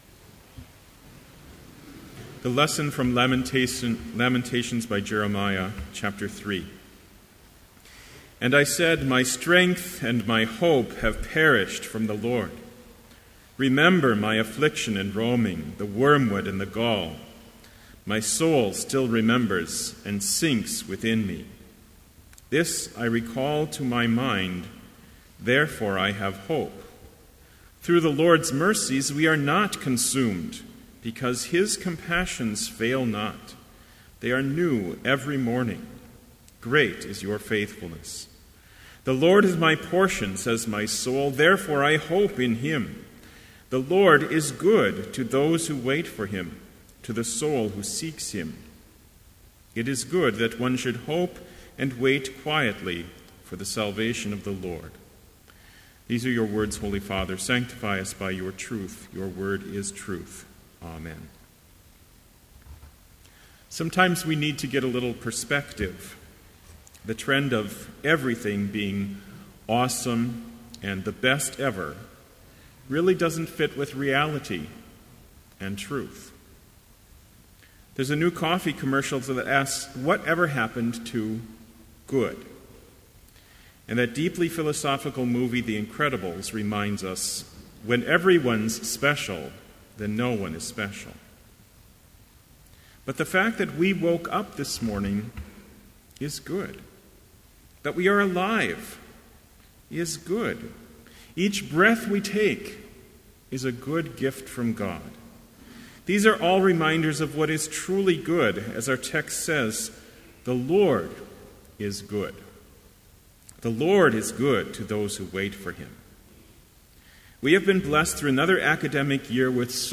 Sermon Only
This Chapel Service was held in Trinity Chapel at Bethany Lutheran College on Tuesday, May 13, 2014, at 10 a.m. Page and hymn numbers are from the Evangelical Lutheran Hymnary.